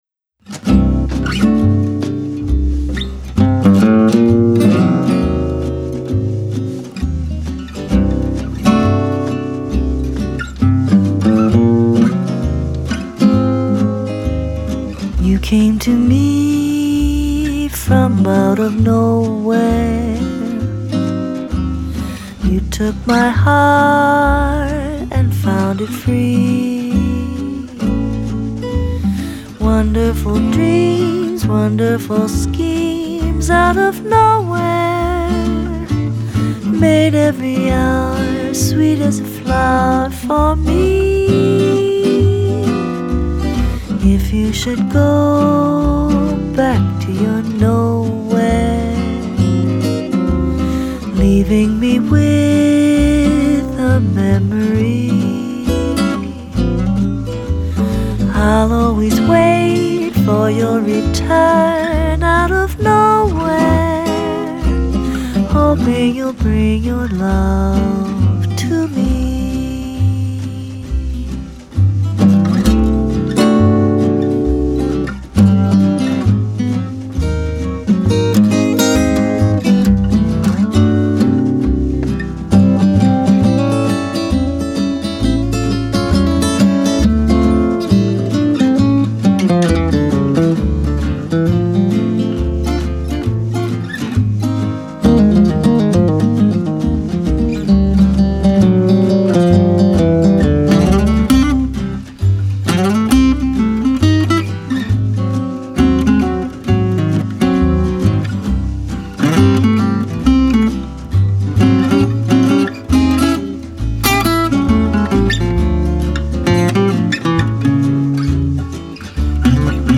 Thème : Jazz